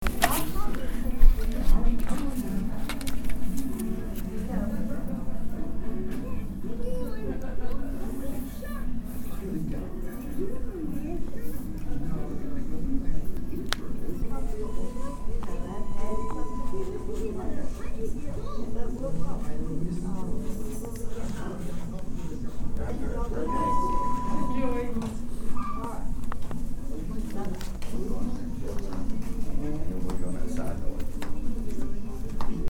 chatter clipboard ding dinging elevator hospital paper papers sound effect free sound royalty free Sound Effects